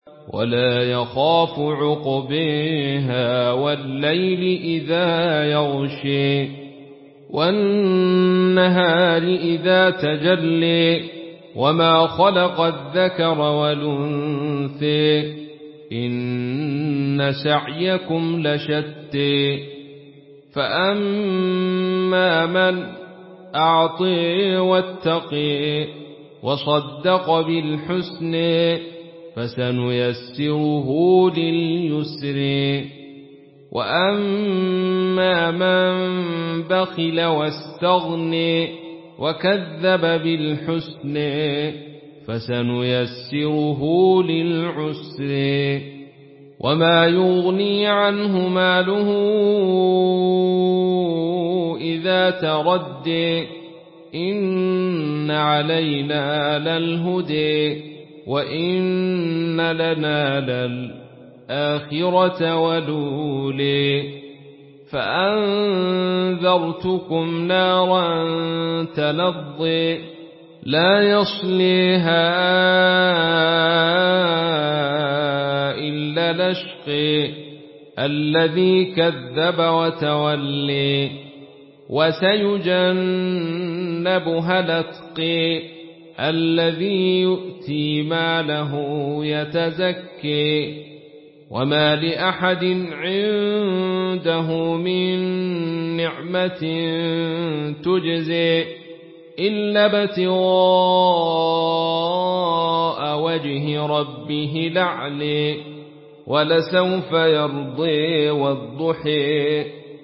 مرتل خلف عن حمزة